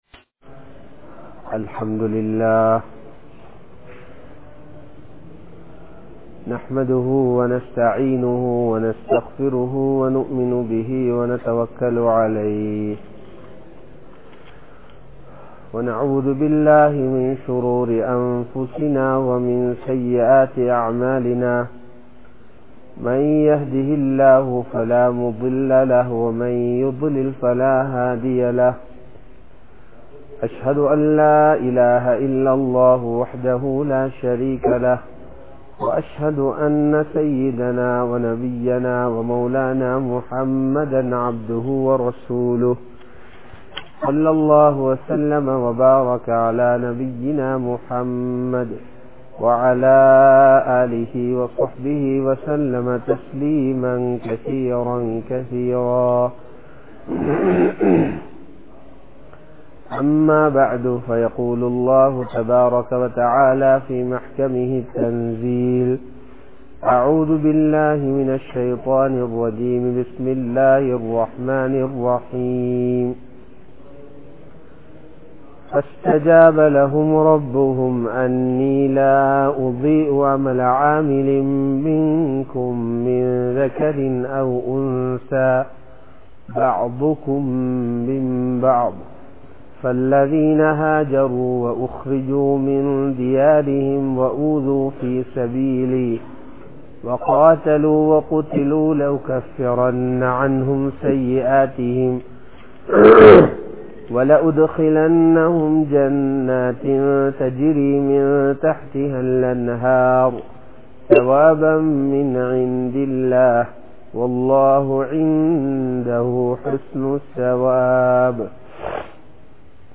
Nimmathi Ethil Undu? (நிம்மதி எதில் உண்டு?) | Audio Bayans | All Ceylon Muslim Youth Community | Addalaichenai